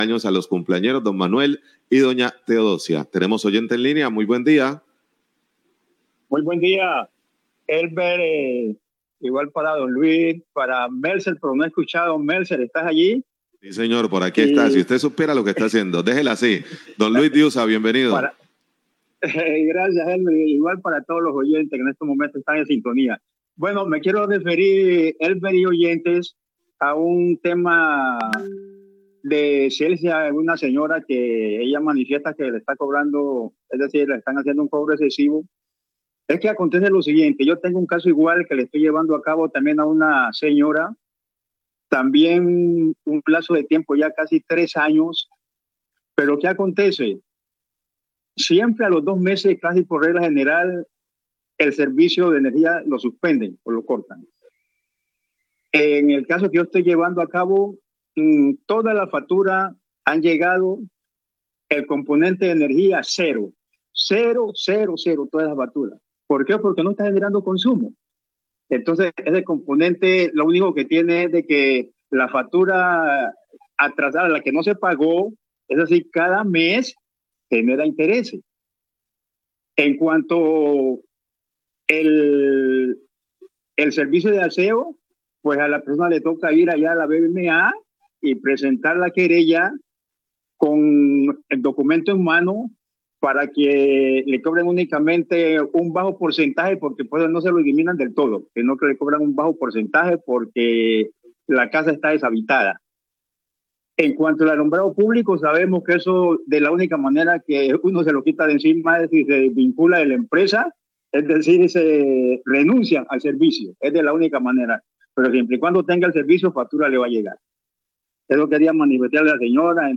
Oyente realiza orientación a queja de usuaria sobre queja presentada en el noticiero,738am
Radio